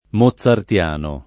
moZZartL#no, meglio che mozzartL#no] agg. — di W. A. Mozart (1756-91) — incerta la lettura it. della -z- tra la sorda del cogn. tedesco e la preval. sonora delle intervocaliche it. scritte scempie